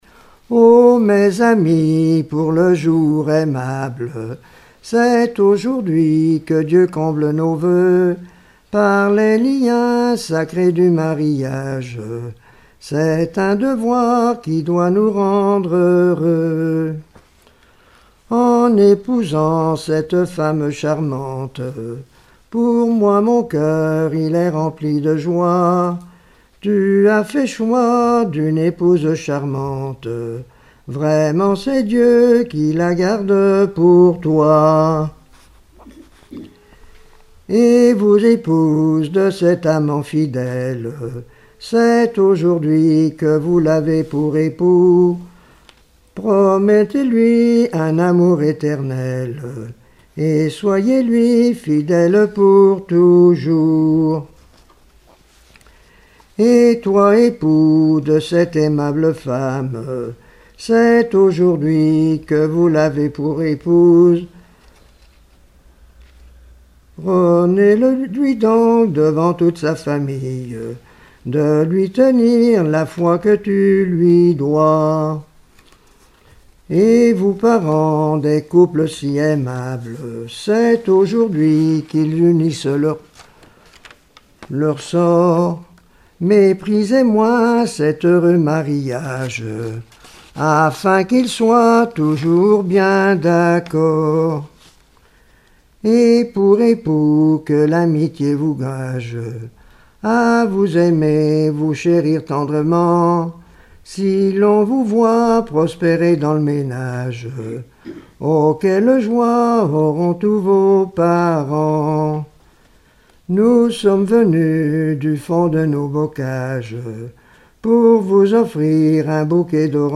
Genre strophique
Collectif-veillée (2ème prise de son)
Pièce musicale inédite